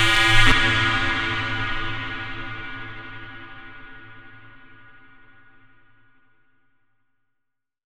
Raver Stab.wav